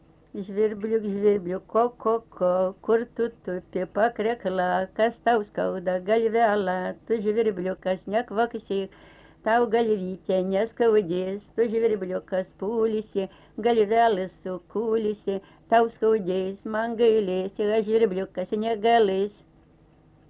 daina, vaikų
Aleksandriškės
vokalinis